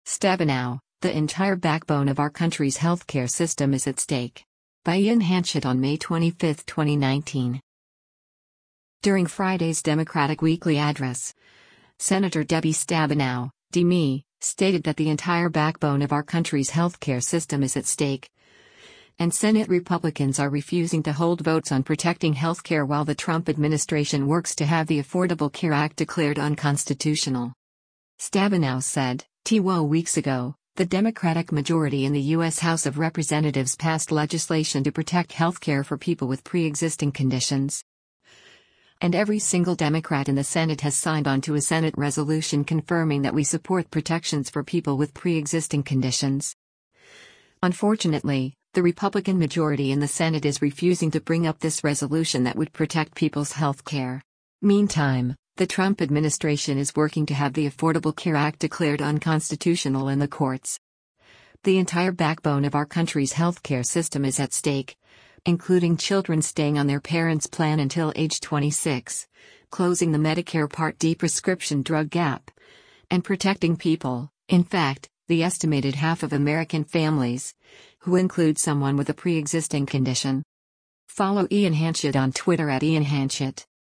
During Friday’s Democratic Weekly Address, Senator Debbie Stabenow (D-MI) stated that “the entire backbone of our country’s healthcare system is at stake,” and Senate Republicans are refusing to hold votes on protecting health care while the Trump administration works to have the Affordable Care Act declared unconstitutional.